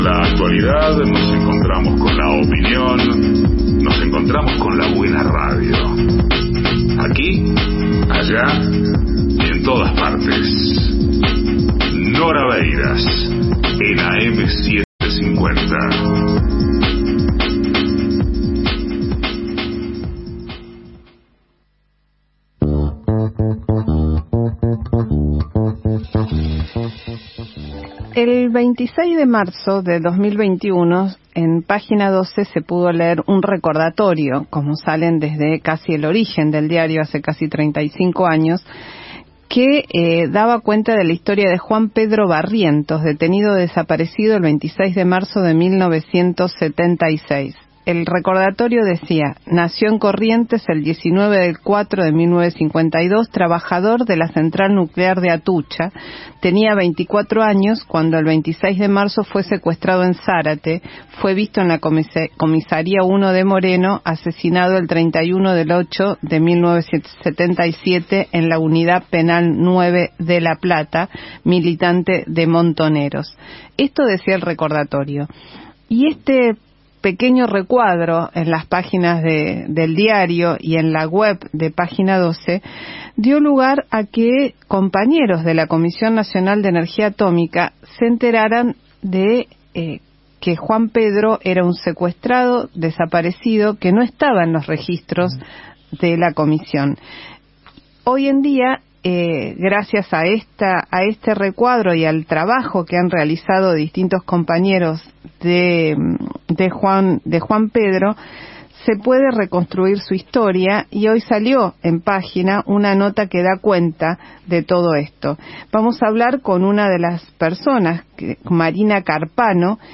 Entrevista
Registro de audio en vivo